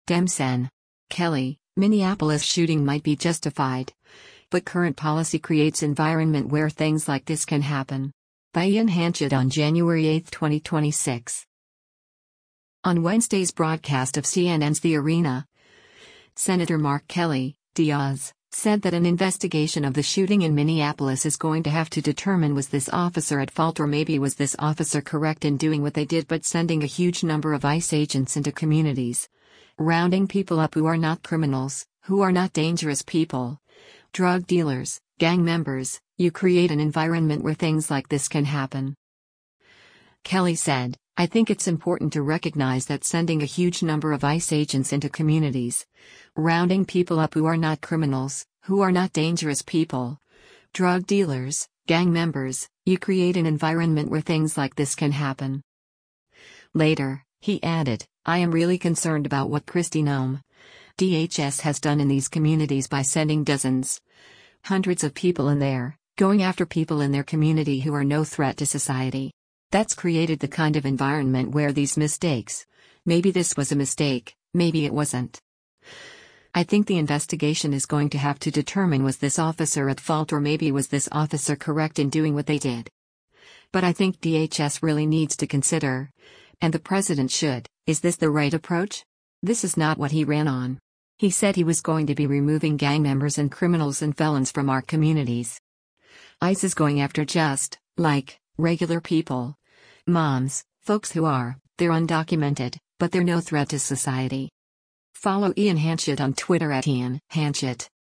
On Wednesday’s broadcast of CNN’s “The Arena,” Sen. Mark Kelly (D-AZ) said that an investigation of the shooting in Minneapolis “is going to have to determine was this officer at fault or maybe was this officer correct in doing what they did” but “sending a huge number of ICE agents into communities, rounding people up who are not criminals, who are not dangerous people, drug dealers, gang members, you create an environment where things like this can happen.”